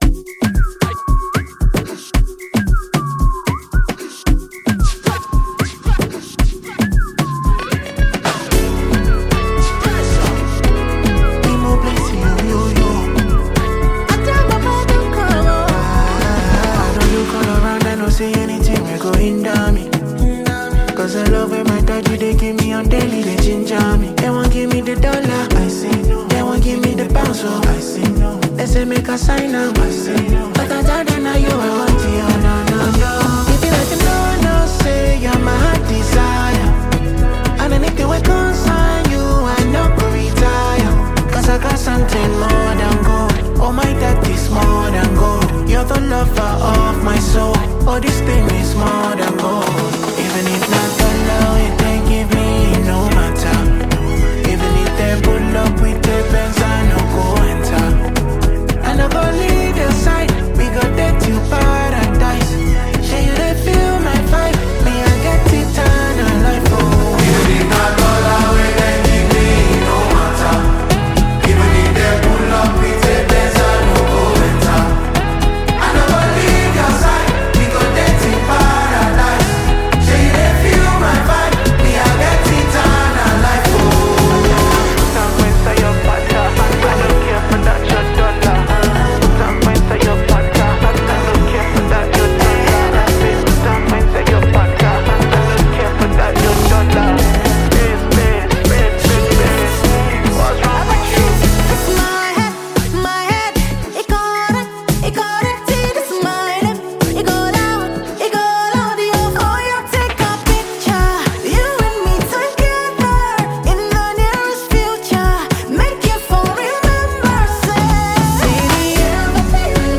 gospel single